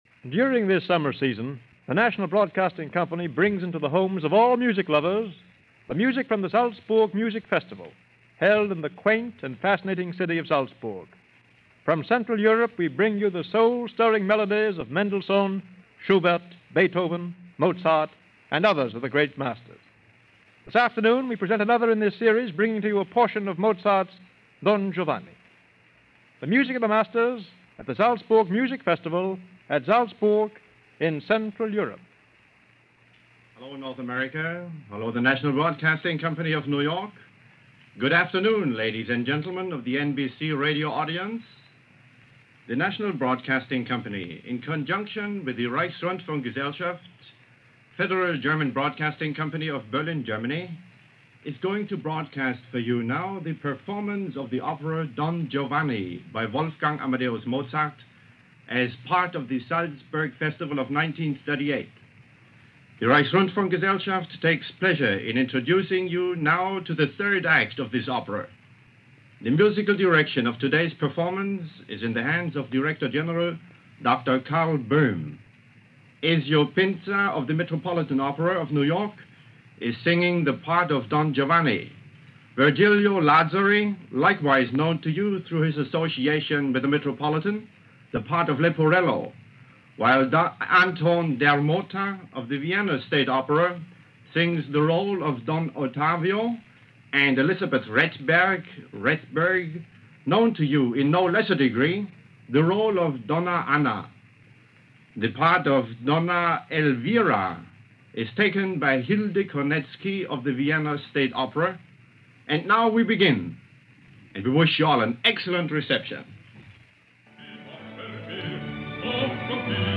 Touted as one of the great innovations in broadcasting, the idea that someone in Los Angeles could hear, as it was happening on stage, a portion of a Festival in Austria by way of a shortwave broadcast was a huge leap in bringing the world closer together via radio.
In this particular case, it was the fabled Salzburg Music Festival, with a performance of Act 3 of Mozart’s Don Giovanni. The all-star cast, led by conductor Karl Böhm featured Ezio Pinza, Virgilio Lazzari, Anton Dermotta and Elizabeth Rethberg. Because Shortwave broadcasting was up to the whims of atmospheric conditions, the sound quality would be a crapshoot – if things were right, it sounded like it was recorded reasonably close – if they were bad, it would impossible to hear anything.